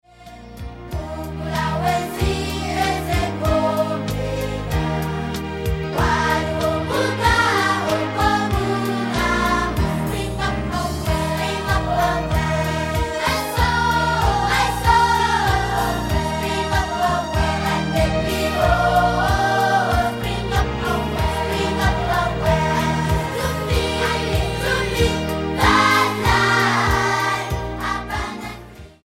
STYLE: Childrens